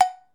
Cowbell_OS_2
Bell Cartoon Cow Cowbell Ding Dong H4n Ring sound effect free sound royalty free Movies & TV